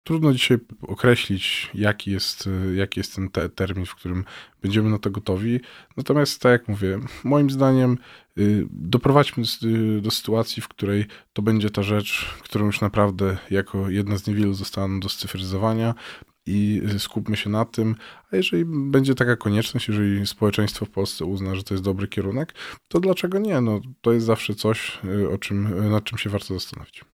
Odpowiada Janusz Cieszyński – sekretarz stanu w KPRM, pełnomocnik rządu do spraw cyberbezpieczeństwa.